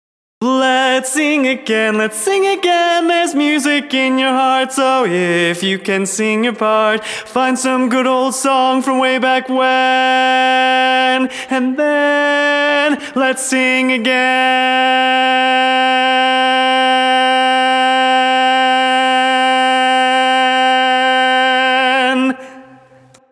Key written in: C Major
Type: Female Barbershop (incl. SAI, HI, etc)
Each recording below is single part only.
Learning tracks sung by